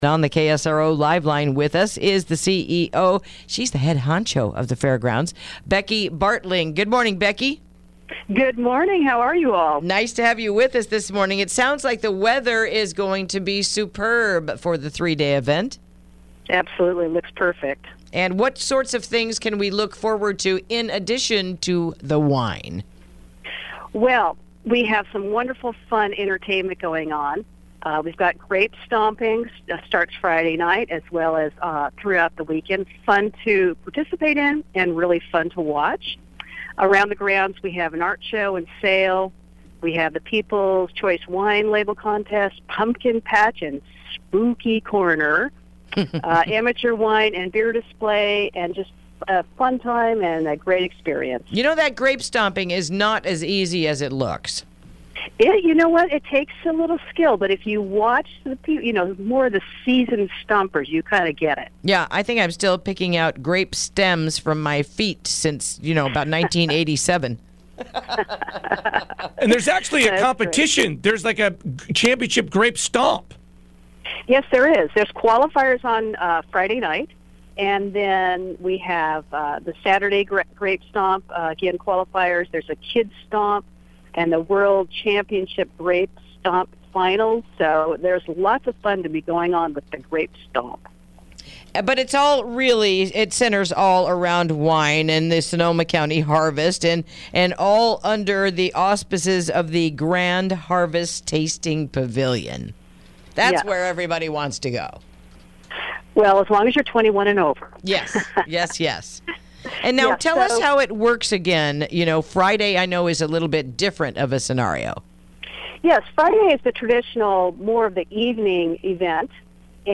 Interview: Harvest Fair is this Weekend